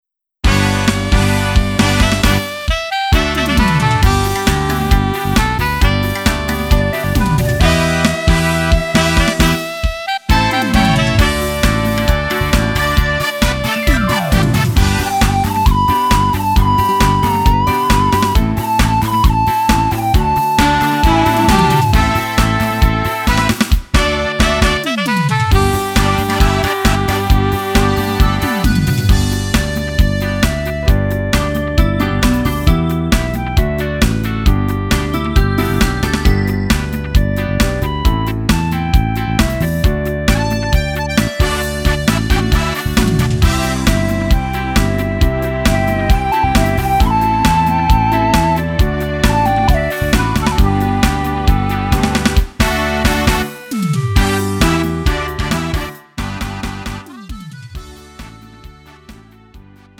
음정 원키 3:41
장르 가요 구분 Lite MR
Lite MR은 저렴한 가격에 간단한 연습이나 취미용으로 활용할 수 있는 가벼운 반주입니다.